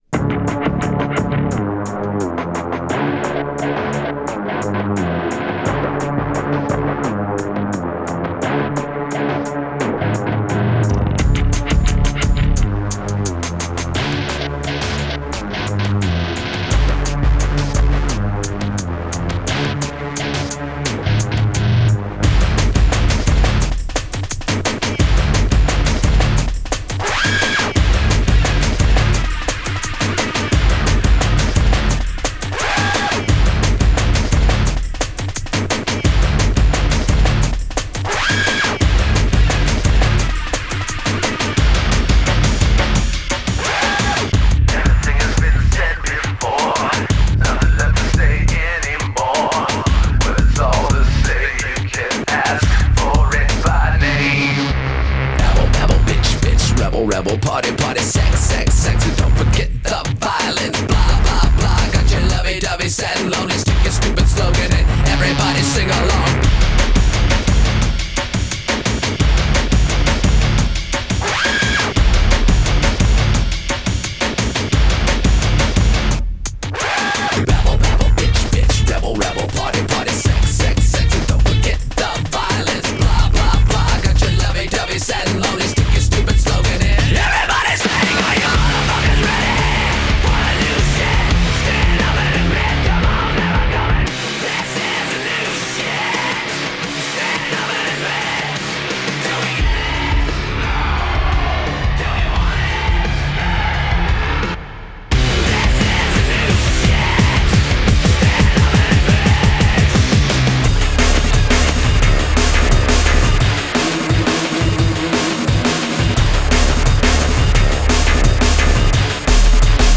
вступление, помоему, долгое...